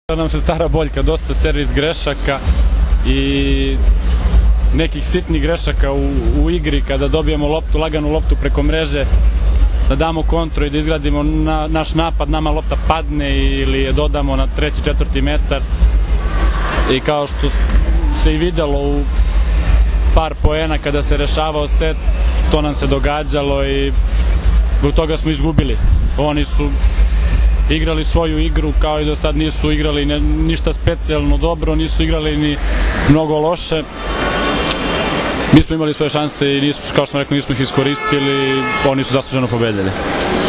IZJAVA SREĆKA LISNICA